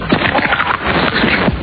steamroller.wav